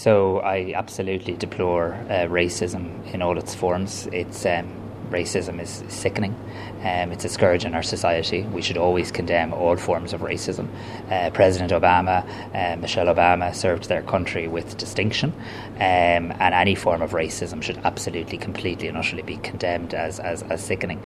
Tánaiste Simon Harris has condemned the video, saying racism has no place in society: